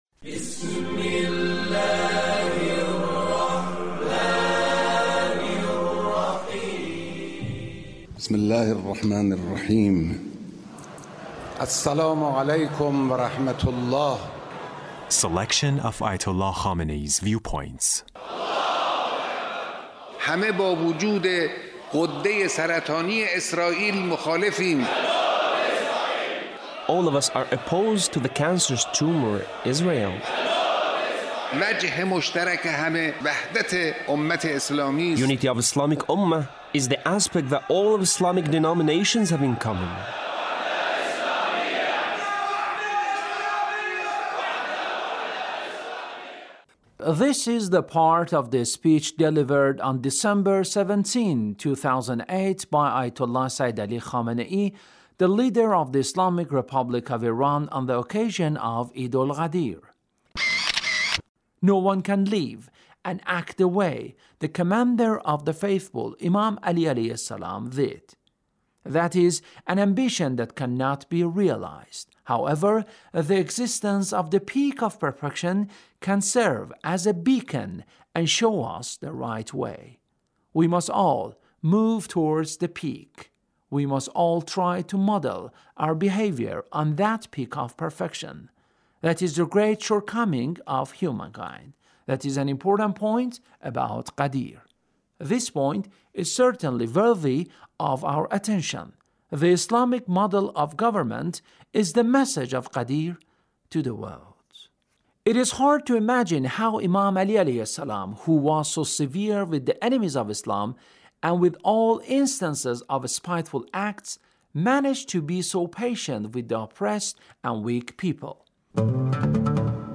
Leader's Speech about Imam Ali a and Ghadir Khom